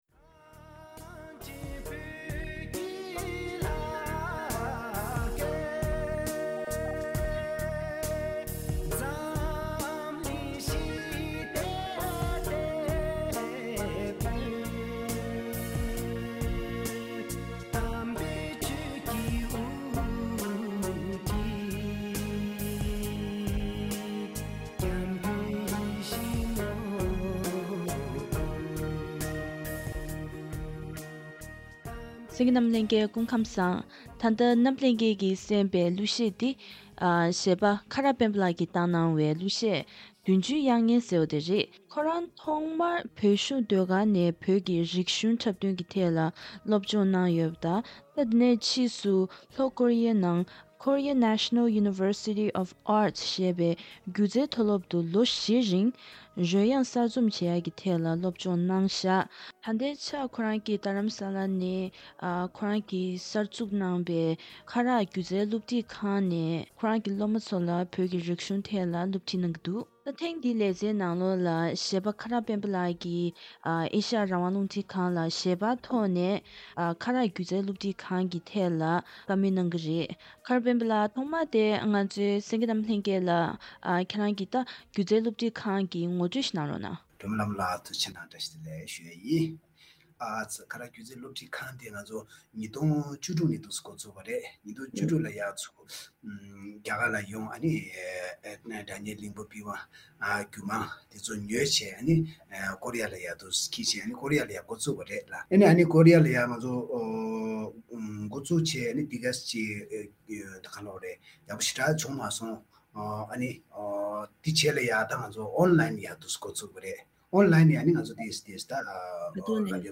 ཞལ་པར་བརྒྱུད་ནས་བཀའ་དྲི་ཞུས་པ་ཞིག་གསན་རོགས་གནང་།